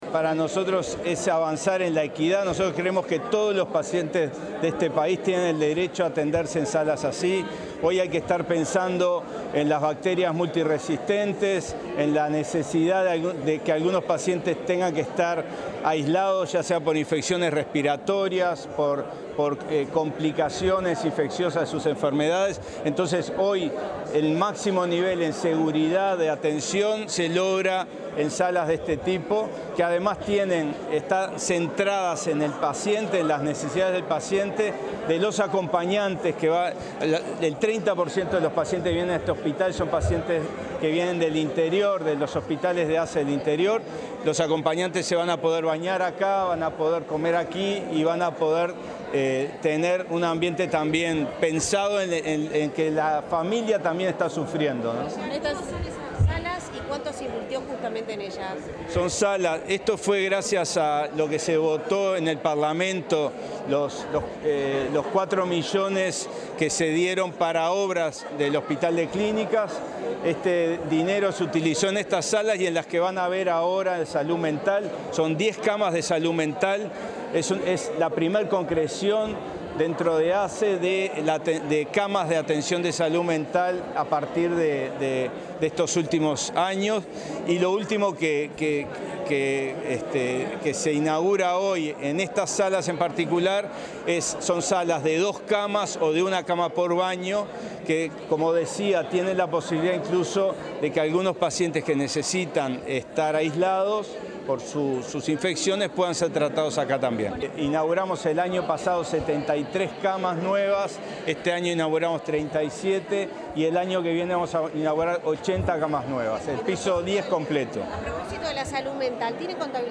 Luego de que se inauguraran salas de internación en el Hospital de Clínicas, el director del centro, Álvaro Villar, efectuó declaraciones a los medios